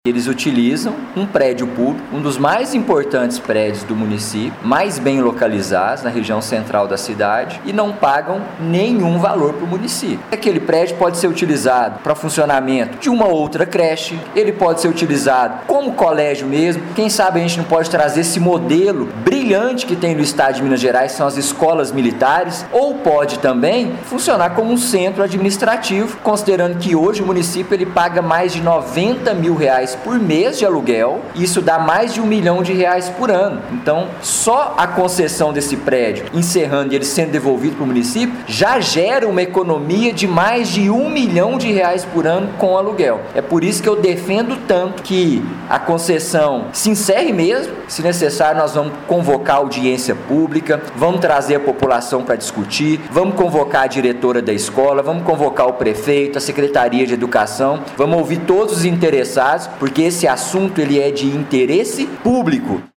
De acordo com o vereador Gustavo Duarte, a questão é de interesse público e o retorno do imóvel localizado na rua Capitão Teixeira, no centro da cidade, só trará benefícios para o município.